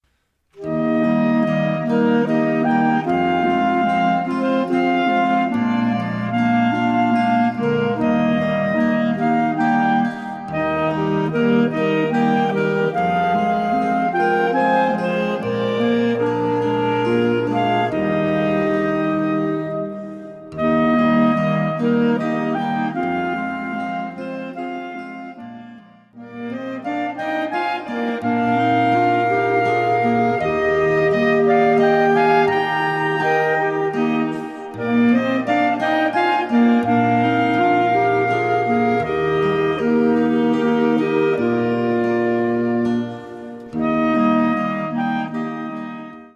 Weihnachtsmusik